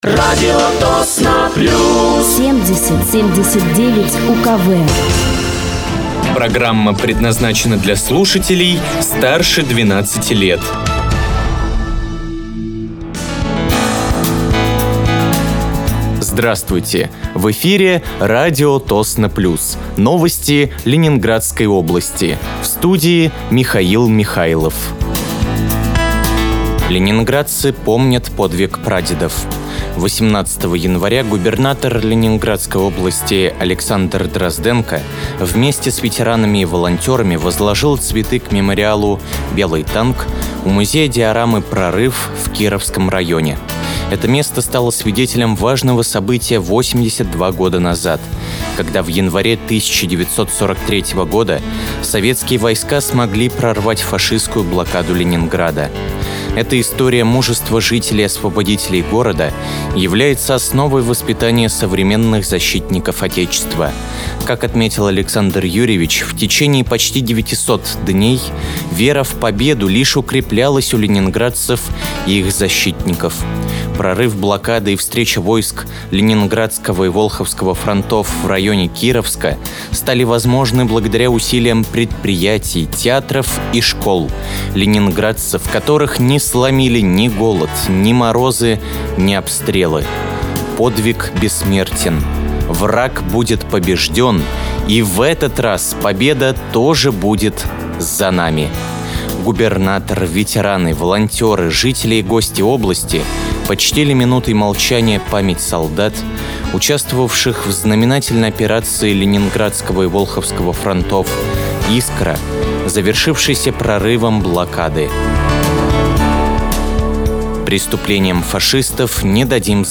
Вы слушаете новости Ленинградской области от 20.01.2025 на радиоканале «Радио Тосно плюс».